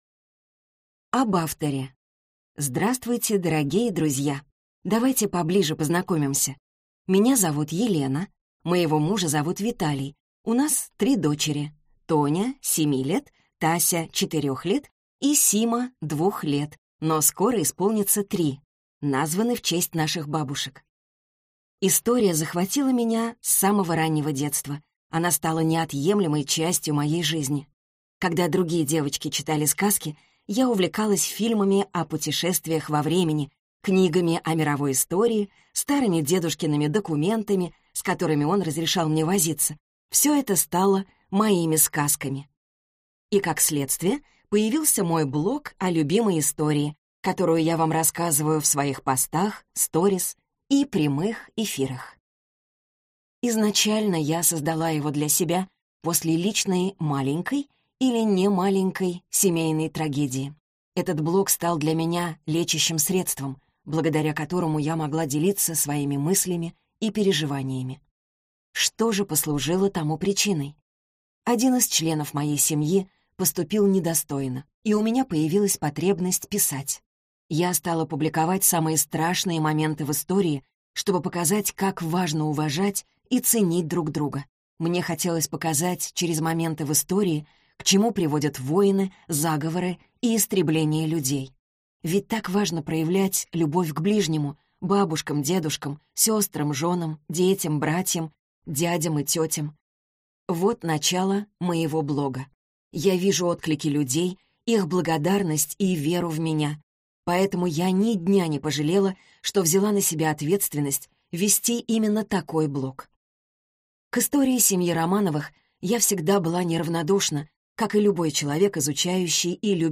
Аудиокнига Последние Романовы. Жизнь семьи. Конец империи | Библиотека аудиокниг